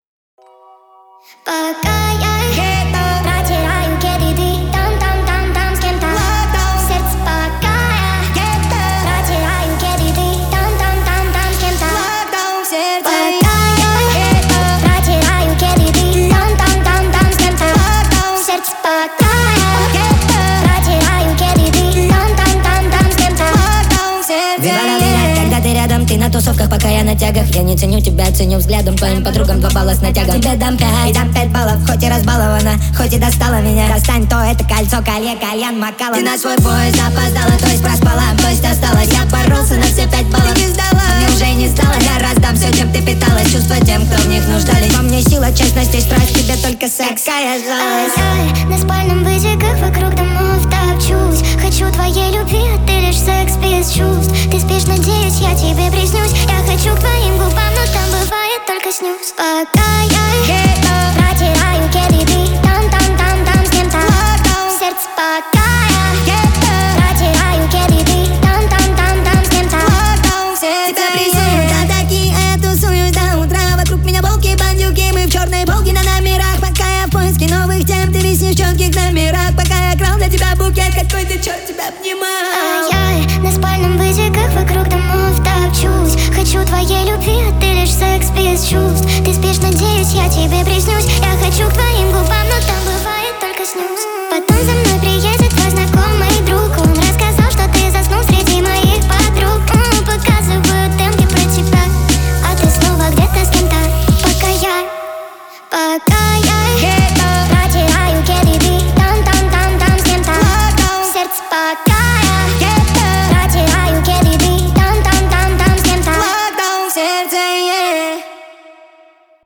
Скачать музыку / Музон / Speed Up